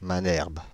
Manerbe (French pronunciation: [manɛʁb]